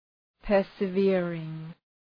Προφορά
{,pɜ:rsə’vırıŋ}